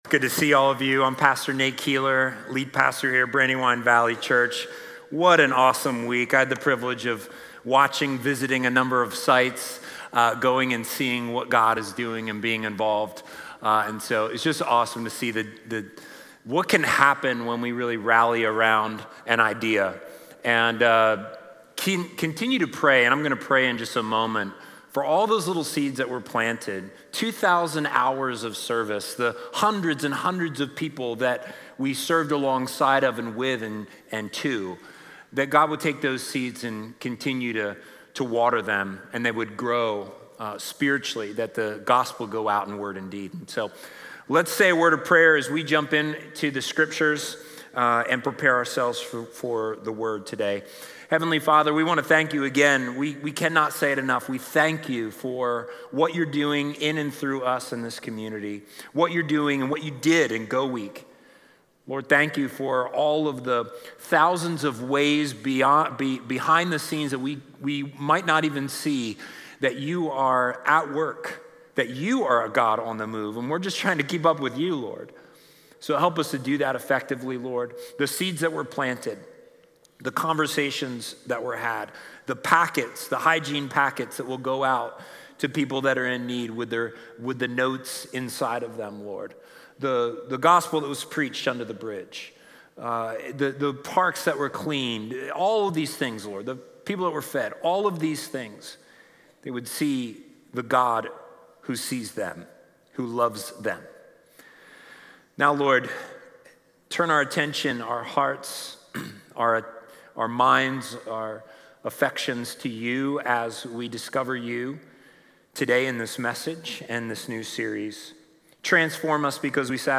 Discover compelling reasons for the credibility of the Bible. Communion will be served during the service.